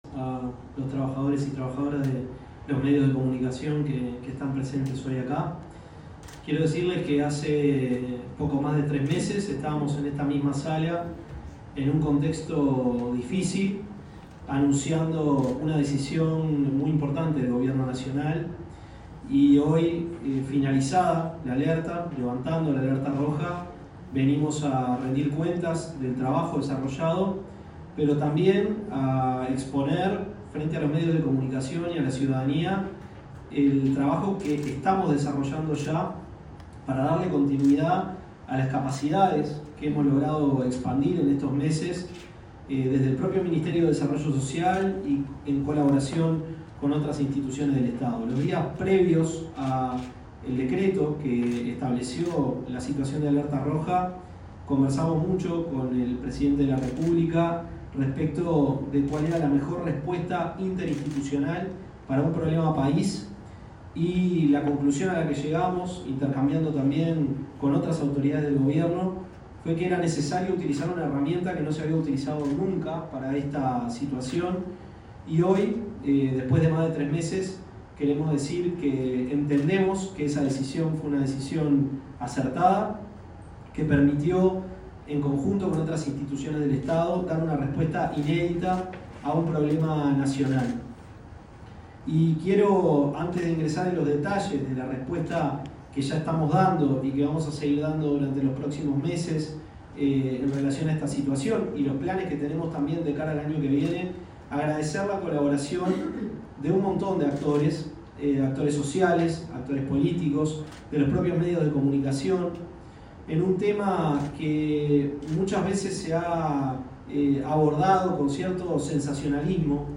Ministerio de Desarrollo Social y Sinae brindaron conferencia de prensa en ocasión del cese de la alerta roja. - NOTICIAS C21
Conferencia-de-prensa-cese-alerta-roja-Civila.mp3